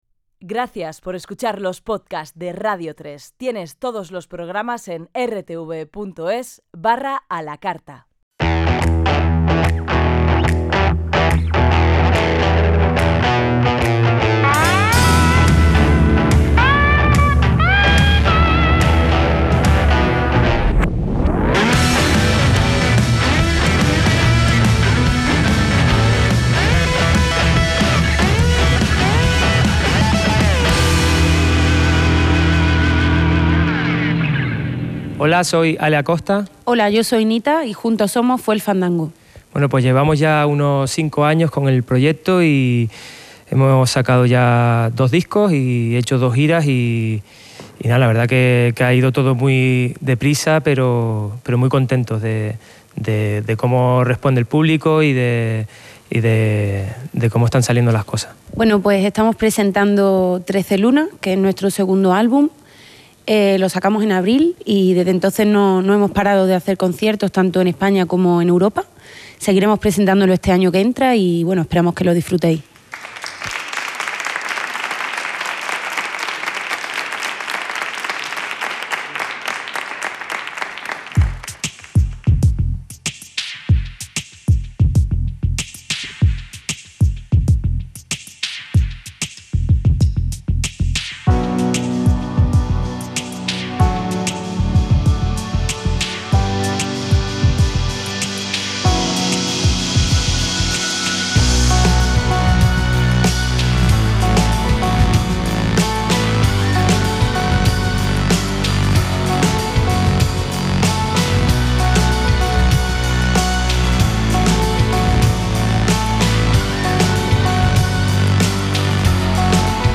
Fusion/Flamenco/Electronica from Spain .
keyboards
A mix of Soul, Funk, Flamenco and Electronica